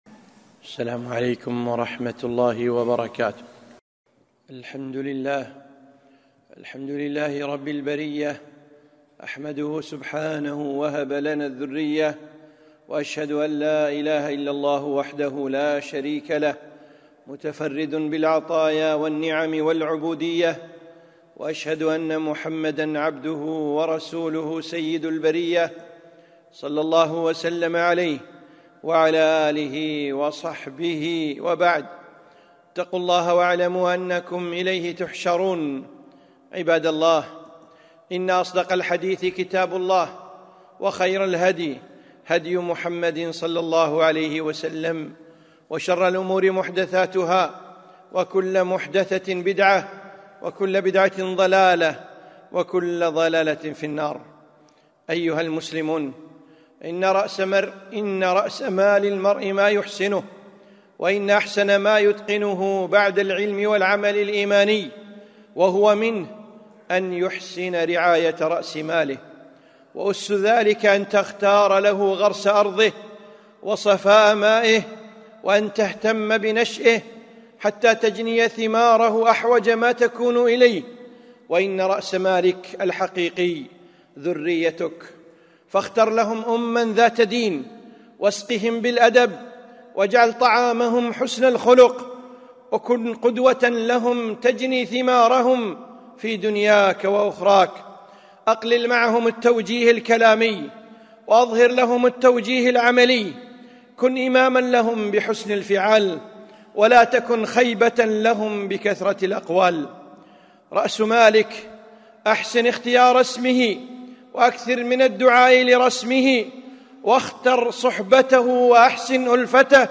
خطبة - رأس مالك